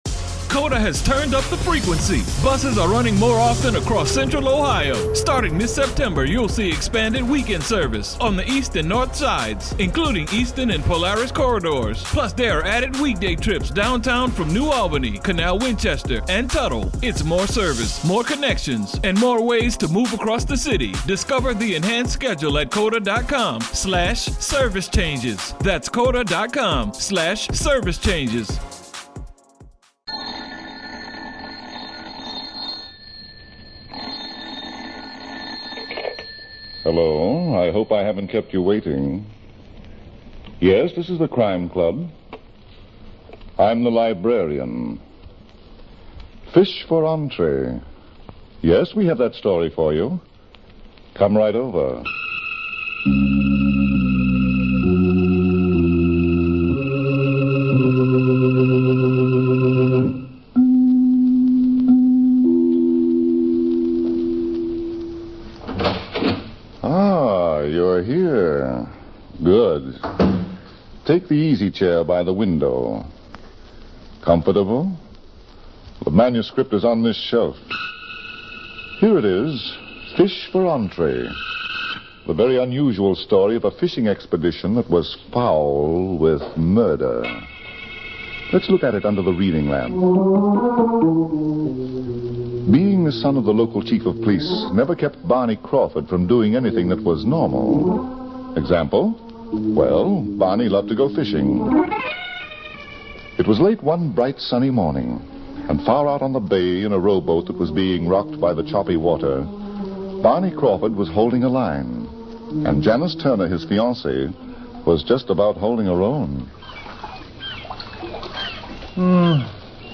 On this episode of the Old Time Radiocast we present you with two stories from the classic radio program The Crime Club!